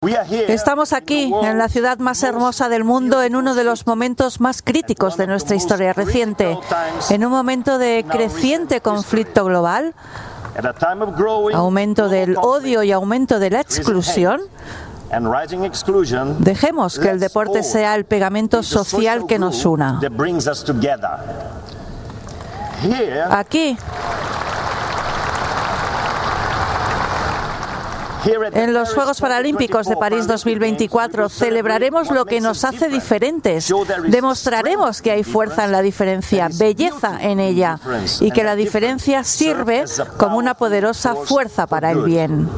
dijo el brasileño Andrew Parsons formato MP3 audio(0,81 MB), presidente del Comité Paralímpico Internacional (CPI)Abre Web externa en ventana nueva, dando la bienvenida a los 4.400 atletas que compiten en el  “evento deportivo más transformador del mundo”, dijo.